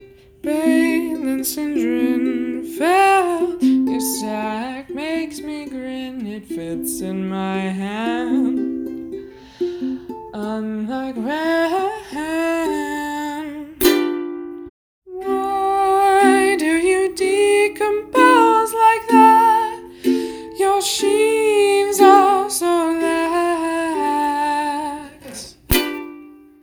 Ukulele Songs from the TamagaWHAT Seminar